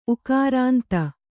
♪ ukārānta